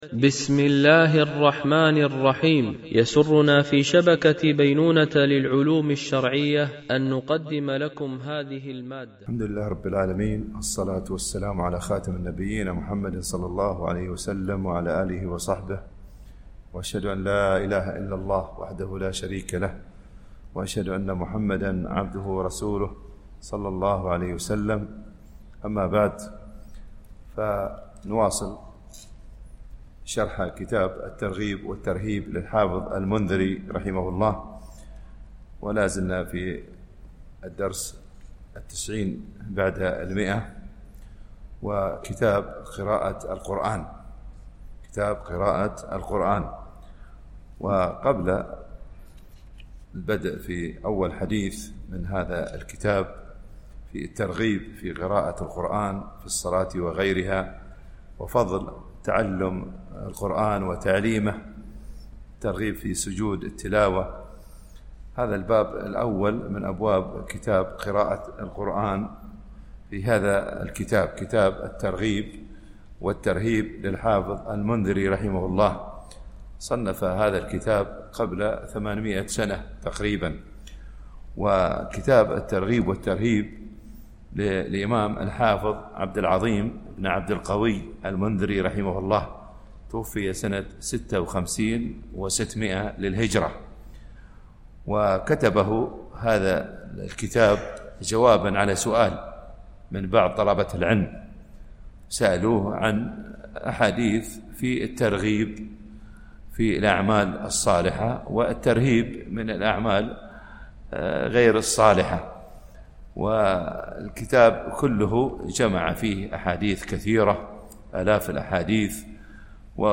MP3 Mono 44kHz 64Kbps (CBR)